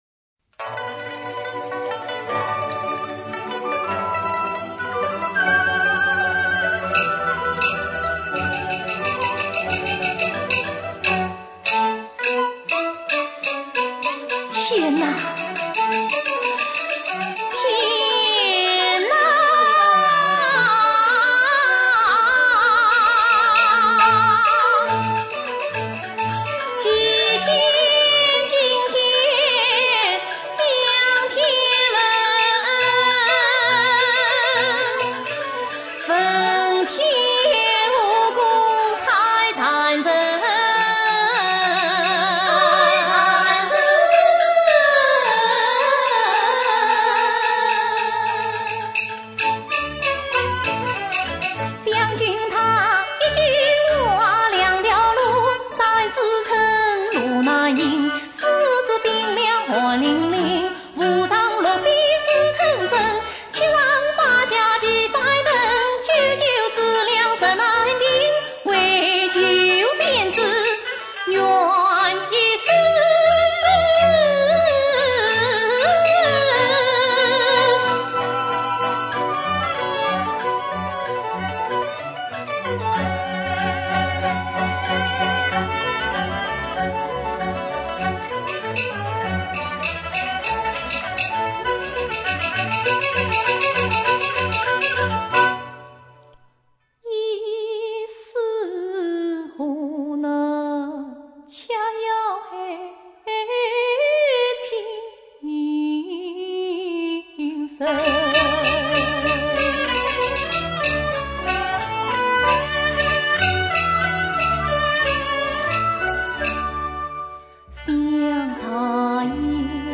音色甜润明亮，运腔韵醇味浓